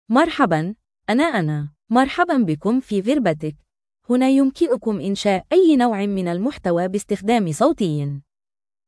FemaleArabic (Standard)
Anna is a female AI voice for Arabic (Standard).
Voice sample
Female
Anna delivers clear pronunciation with authentic Standard Arabic intonation, making your content sound professionally produced.